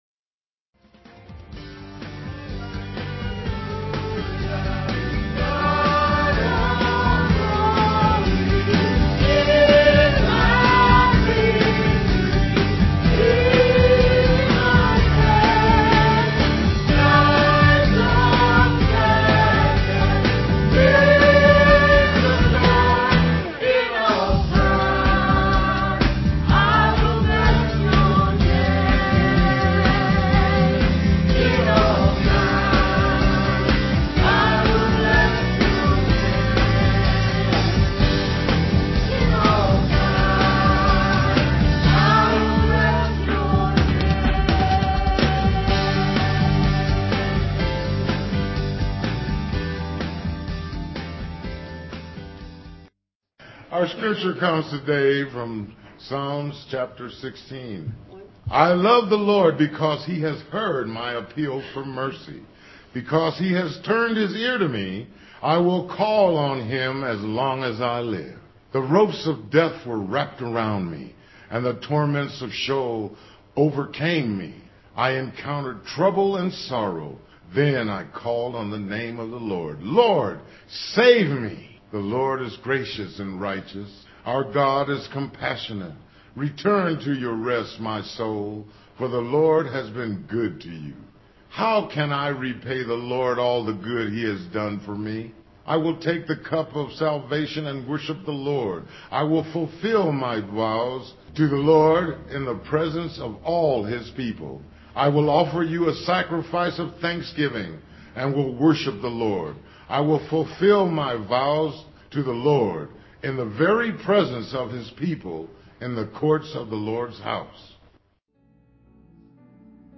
Scripture: Psalm 116, excerpts read
Piano and organ duet